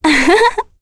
Ophelia-vox-Happy1.wav